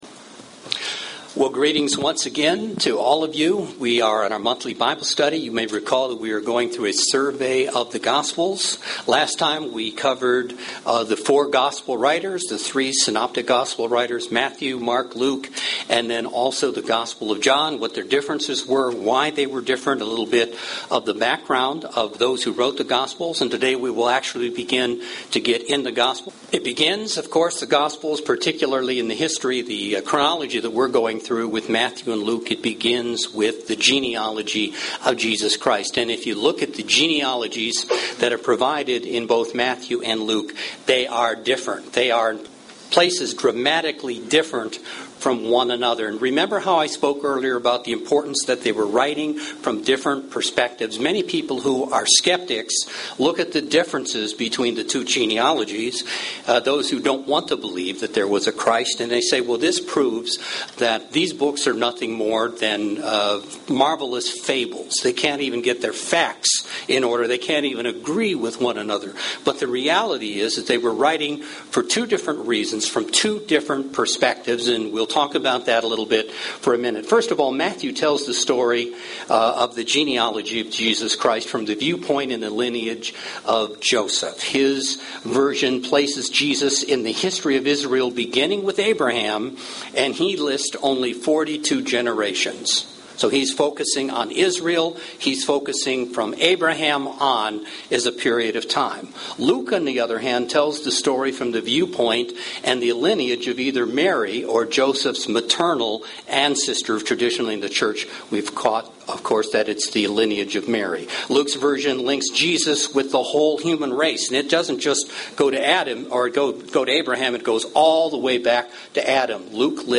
In the continuation of this Bible study series, we discuss the significance of the two genealogies given for Jesus Christ.